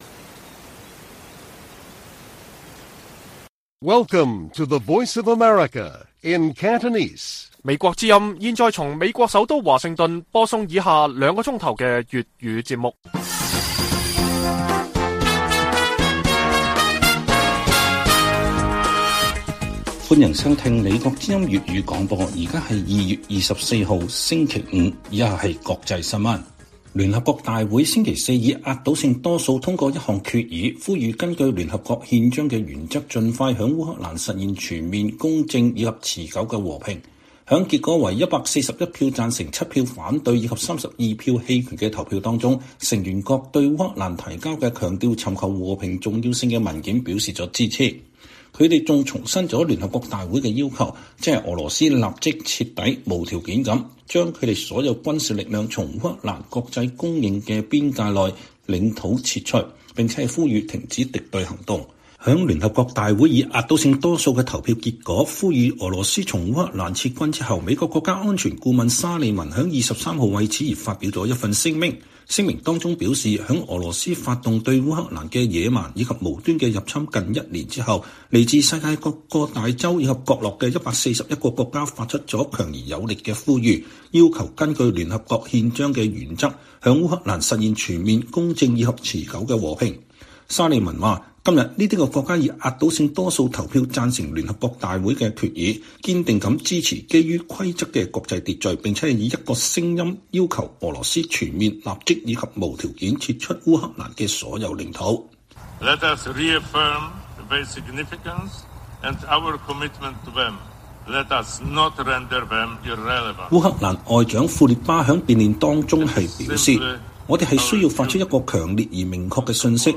粵語新聞 晚上9-10點: 聯合國以壓倒多數通過決議呼籲烏克蘭公正持久和平